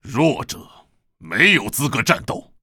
文件 文件历史 文件用途 全域文件用途 Hartz_amb_03.ogg （Ogg Vorbis声音文件，长度2.6秒，104 kbps，文件大小：33 KB） 源地址:地下城与勇士游戏语音 文件历史 点击某个日期/时间查看对应时刻的文件。